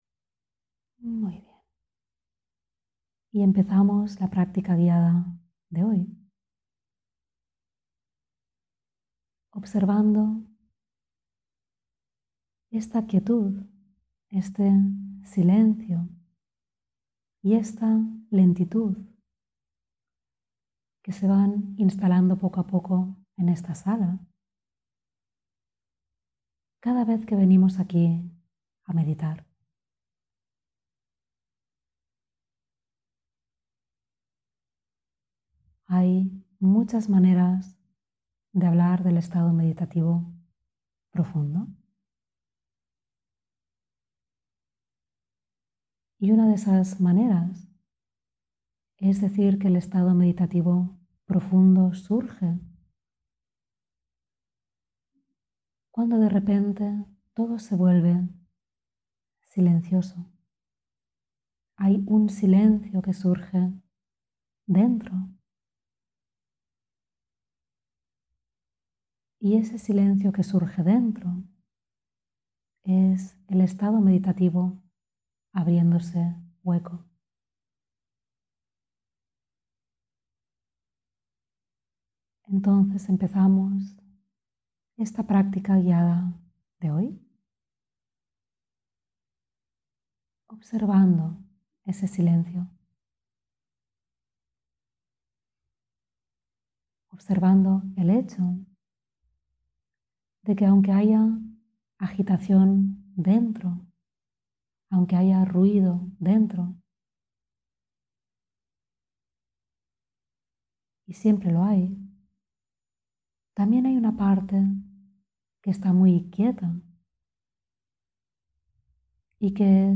mindfulness_meditacion_guiada_barcelona-1.mp3